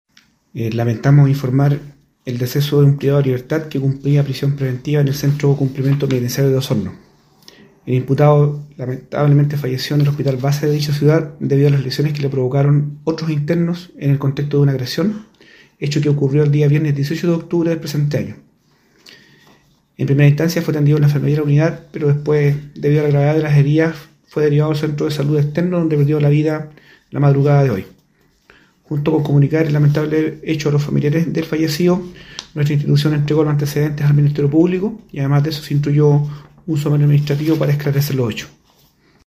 El director regional de Gendarmería en Los Lagos, coronel Edgardo Caniulef Gajardo, se refirió a esta situación.